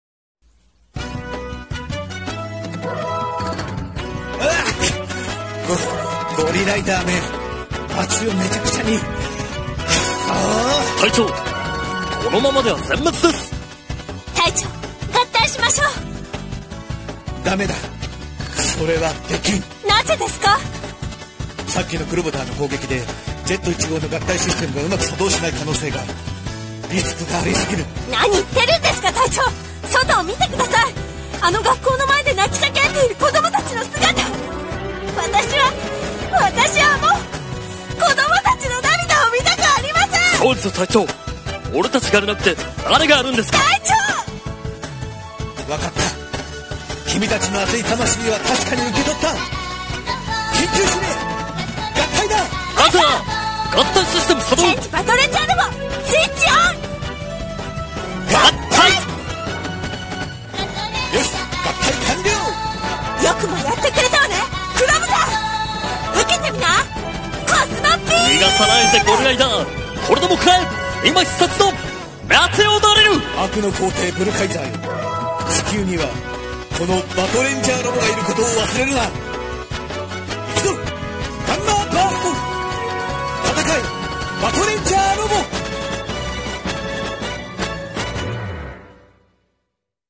（声劇）戦え！バトレンジャーロボ